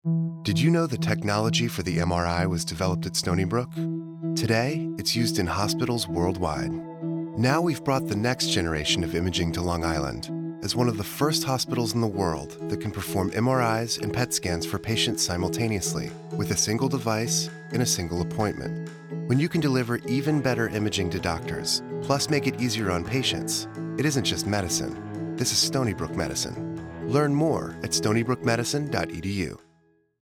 "Did You Know" :30 Radio Spot